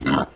Index of /App/sound/monster/wild_boar_god
damage_1.wav